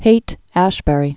(hātăshbĕr-ē, -bə-rē)